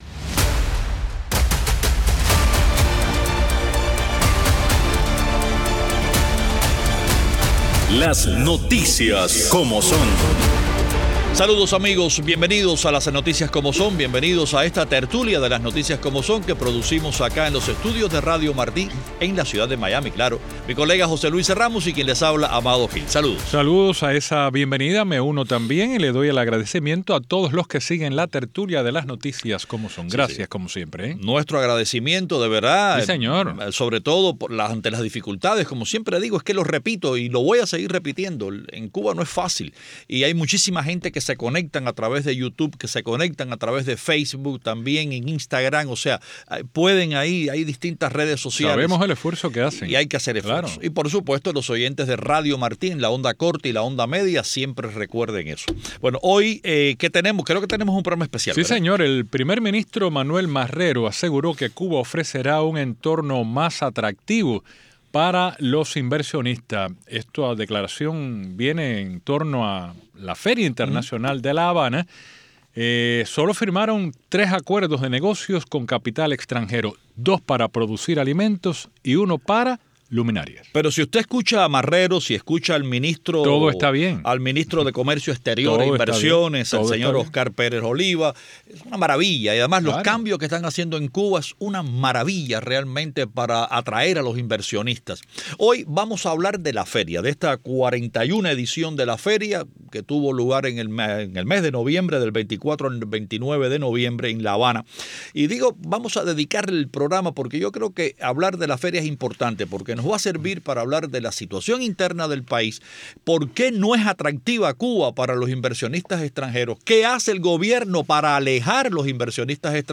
una tertulia especial